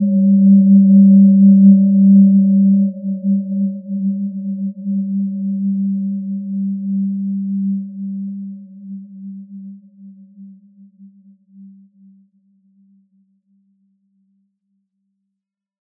Gentle-Metallic-3-G3-p.wav